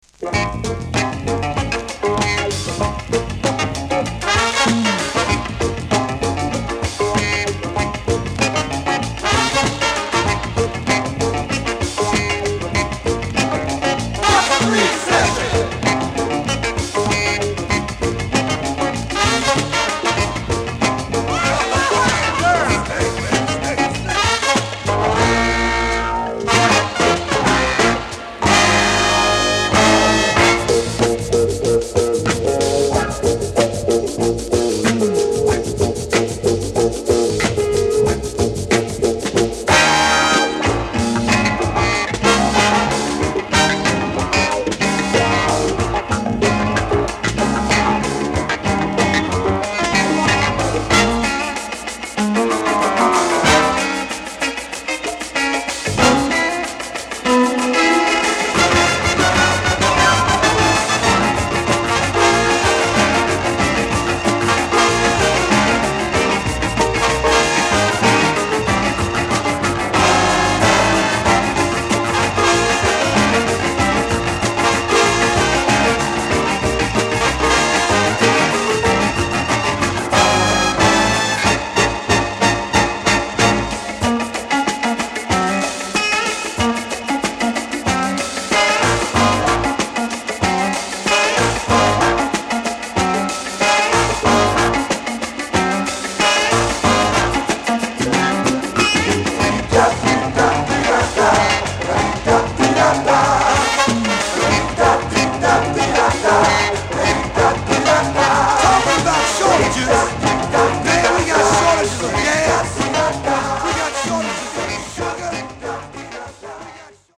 JBスタイルのディープなファンク・チューン